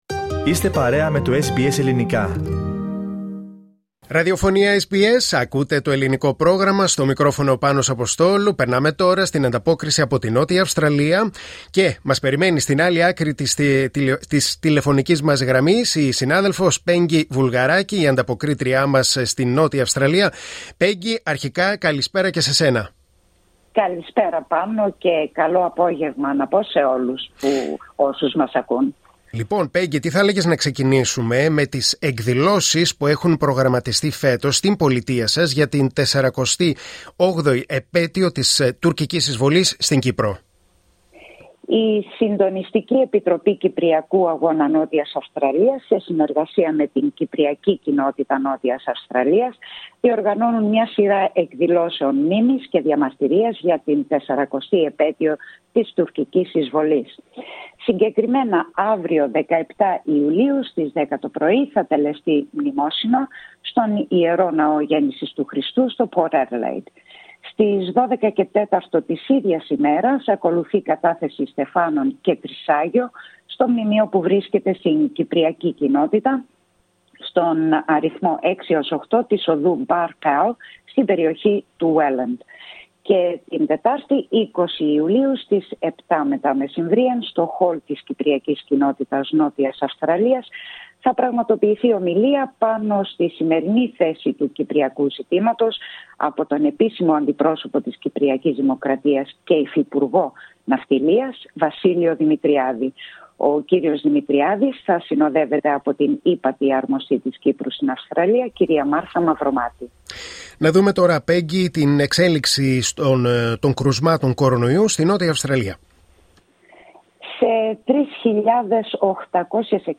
Η εβδομαδιαία ανταπόκριση από την Αδελαΐδα της Νότιας Αυστραλίας.